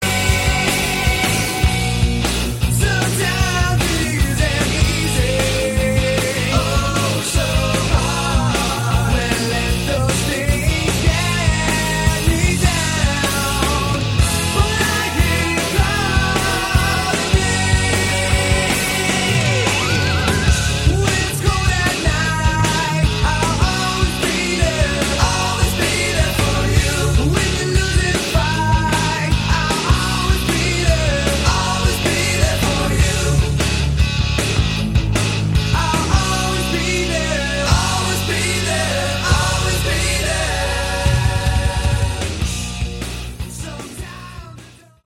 Category: Hard Rock
Vocals, Guitars, Keyboards, Piano